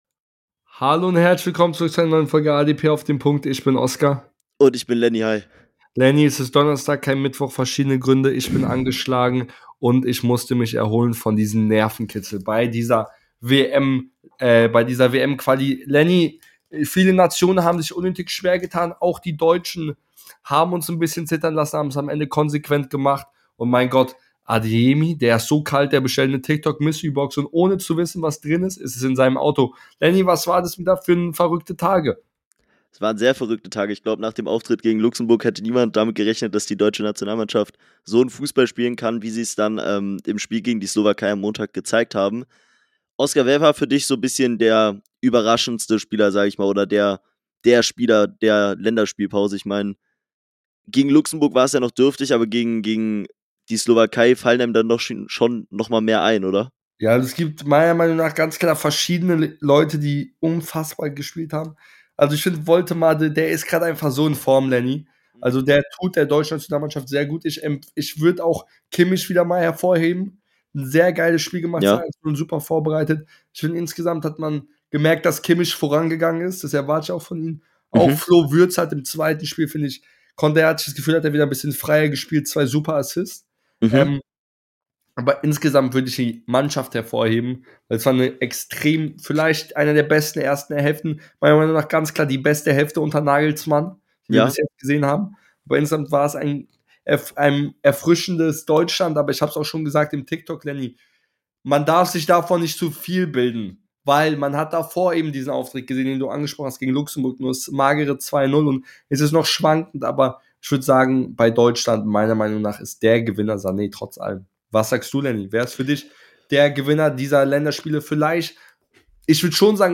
In der heutigen Folge sprechen die beiden Hosts über die WM Quali , tippen die Playoffs und sagen welche Trainerstühle in der Bundesliga wackeln